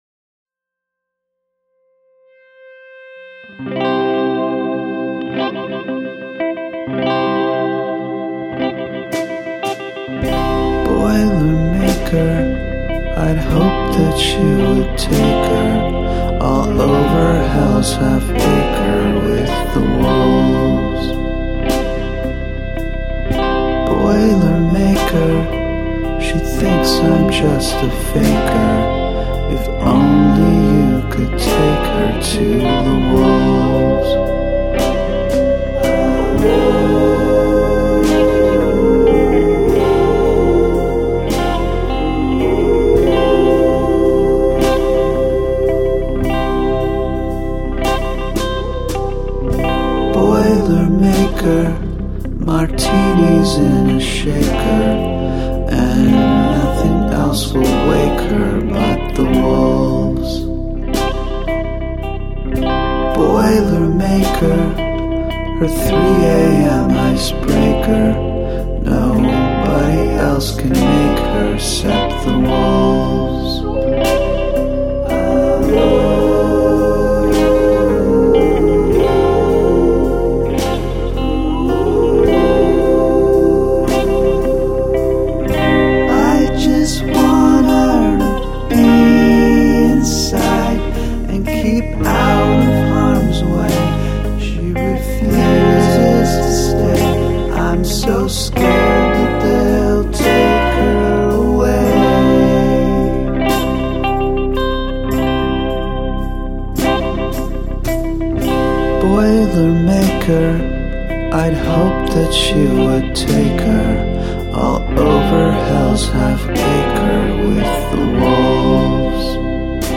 Integrate animal sounds into your song's structure